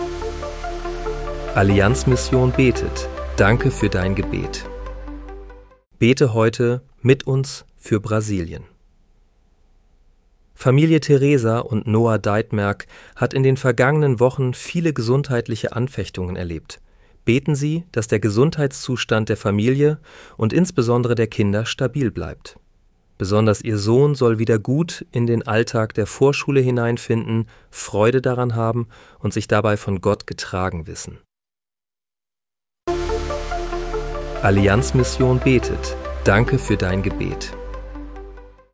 Bete am 26. Februar 2026 mit uns für Brasilien. (KI-generiert mit